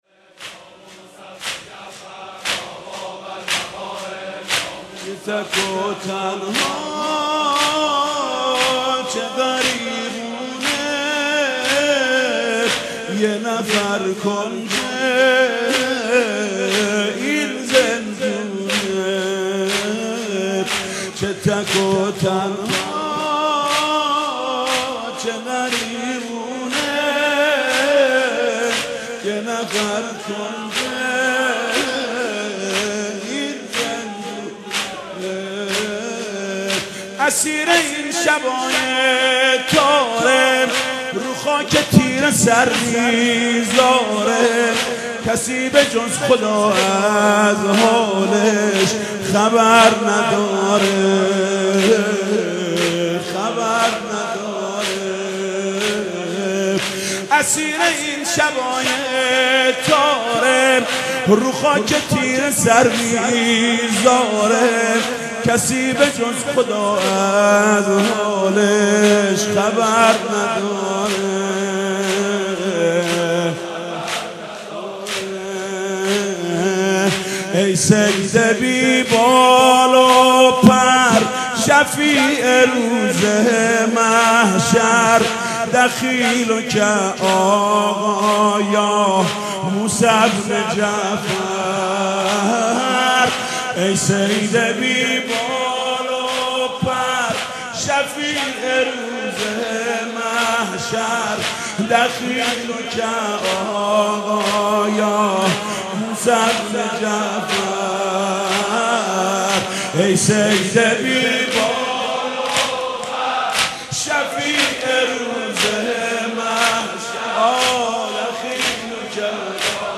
مداحی جانسوز حاج محمود کریمی برای شهادت حضرت موسی بن جعفر علیه السلام را خواهید شنید.